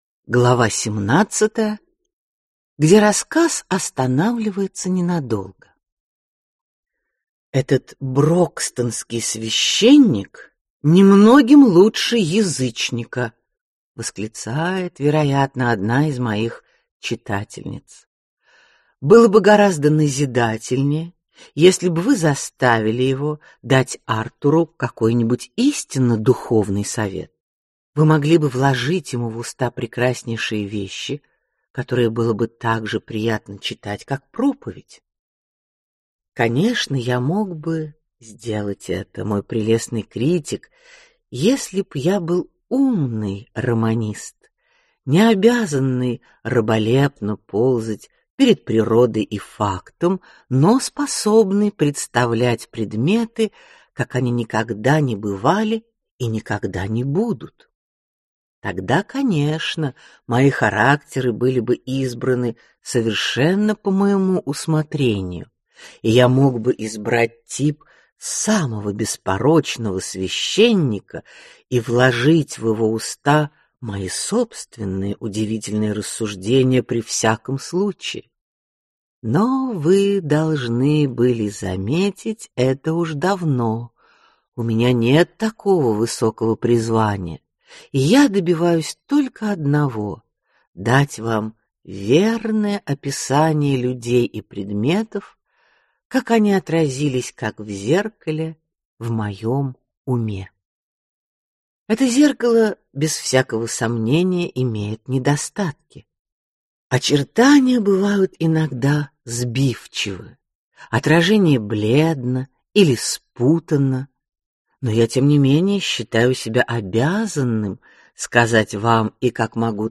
Аудиокнига Адам Бид. Часть 2 | Библиотека аудиокниг